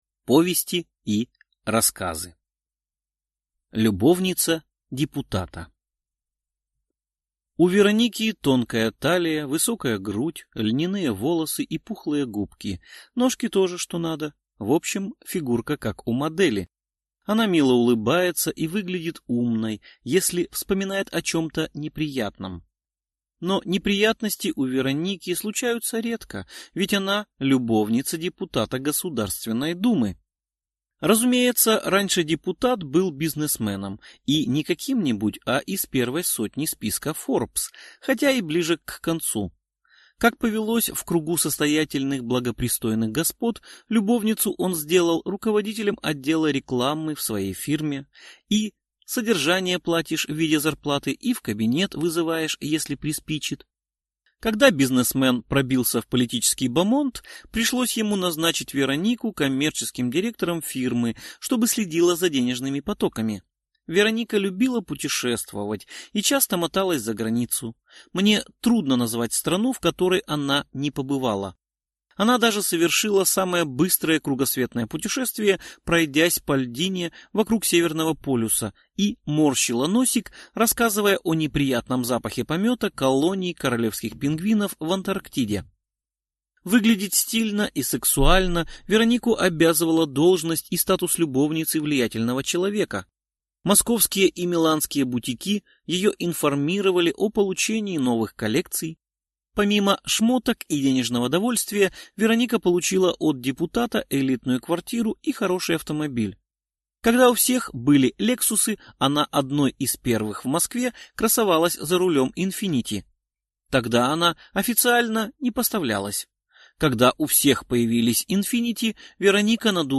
Аудиокнига Любовница депутата (сборник) | Библиотека аудиокниг